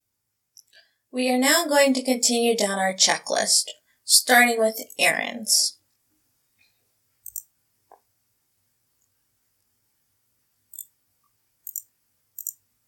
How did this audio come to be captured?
Complete Beginner - Audio sounds echoy/reverby? Following the instructions in the video, I came up with this recording (see attachment), which, unlike the video, seems to be more intelligible.